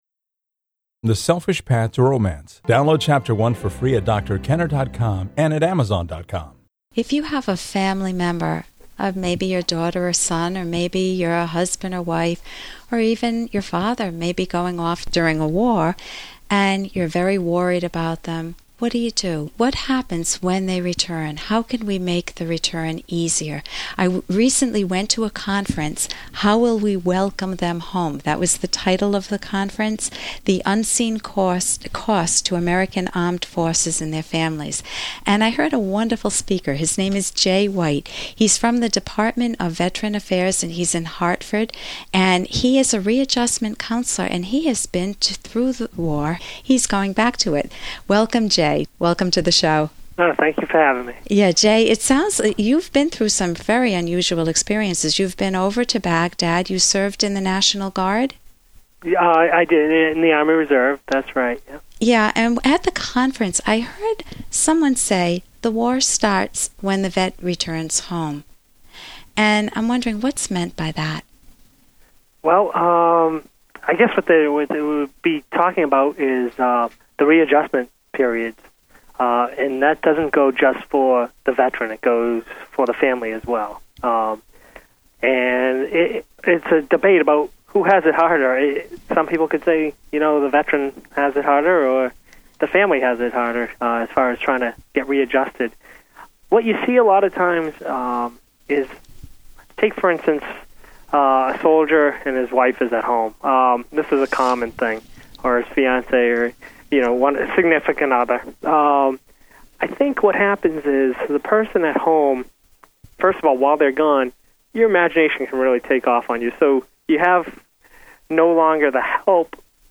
Soldier Counseling ~ What a soldier experiences after returning home from war. A short interview